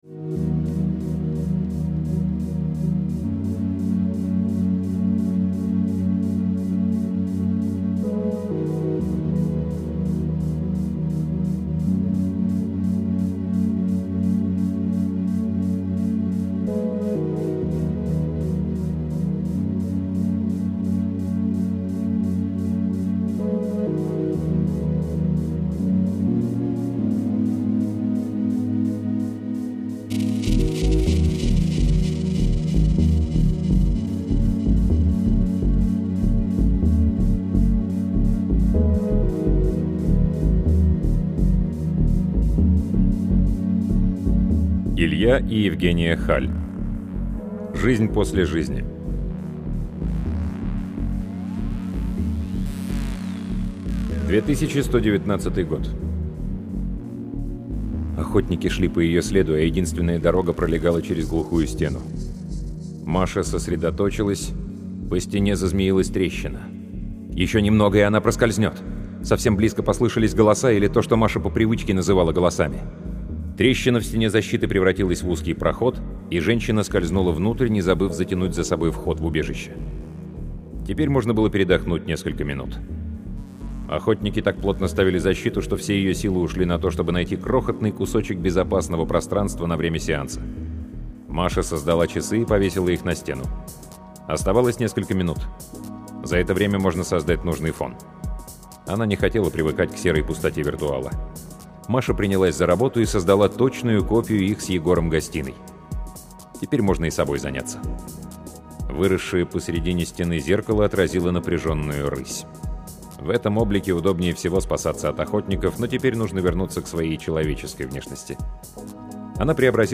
Аудиокнига Илья и Евгения Халь — Жизнь после жизни